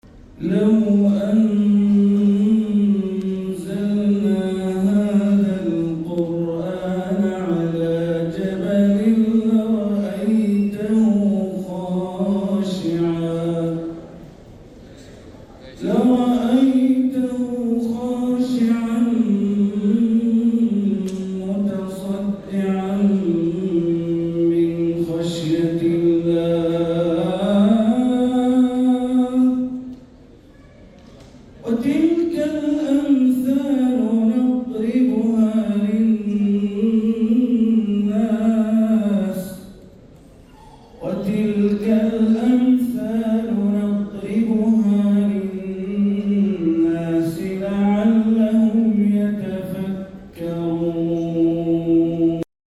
تلاوة في حفل تكريم الفائزين من المسابقة الدولية للقرآن الكريم في دول البلقان > زيارة الشيخ بندر بليلة الى جمهورية كوسوفو > تلاوات وجهود أئمة الحرم المكي خارج الحرم > المزيد - تلاوات الحرمين